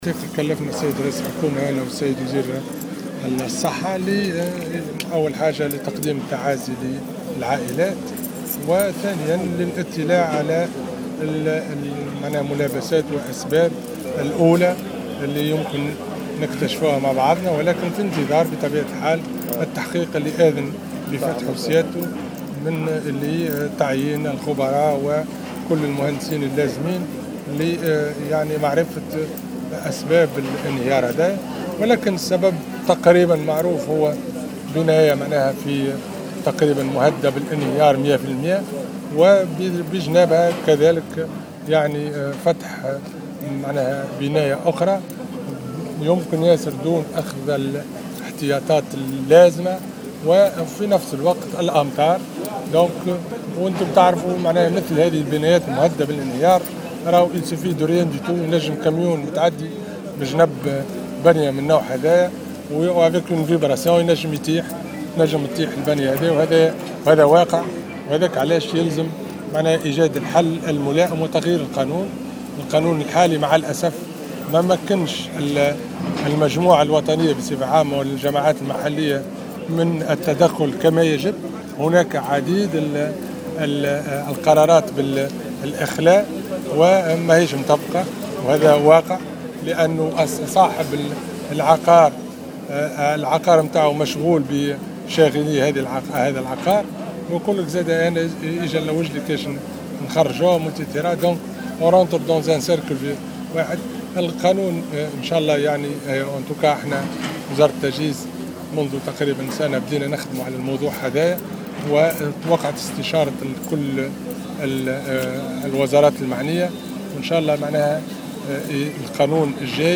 Dans une déclaration accordée à Jawhara FM, le ministre a indiqué que 200 autres habitations situées de part et d’autres de la Tunisie, sont menacées d’effondrement.